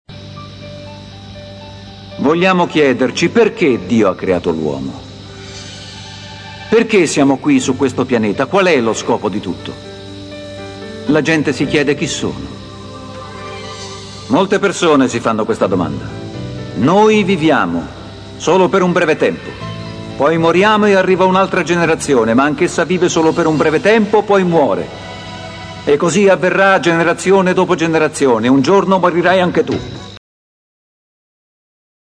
Predicazioni
Brevi audio clip tratti da un messaggio evangelistico di Billy Graham